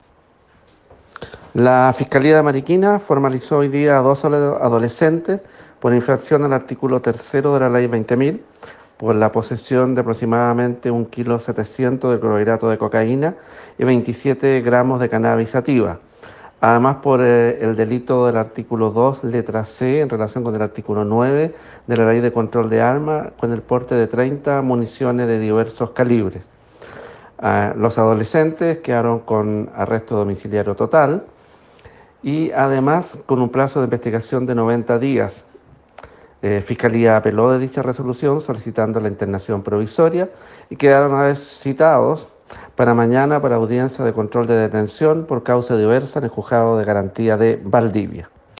Fiscal Alejandro Ríos